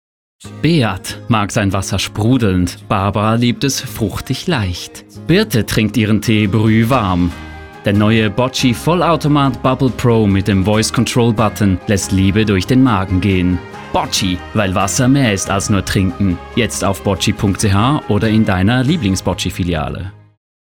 Werbung Botchi (fiktiv)